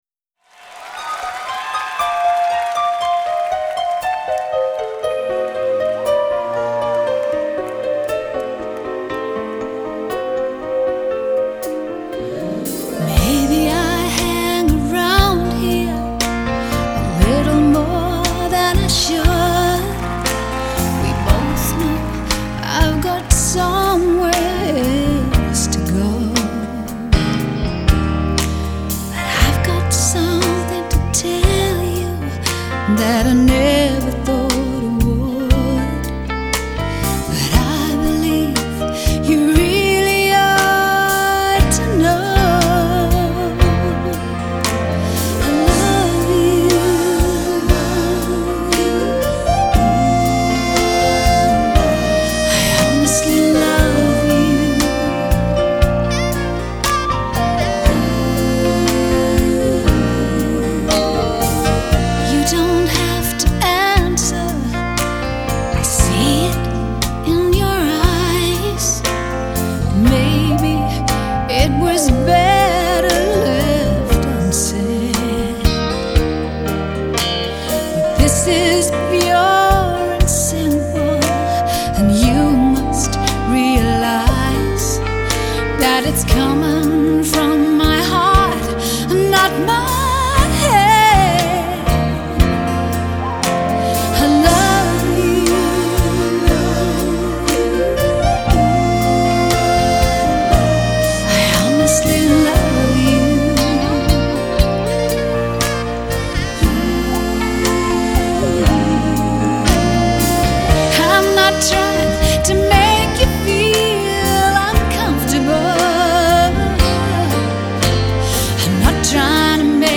австралийской певицы
баллада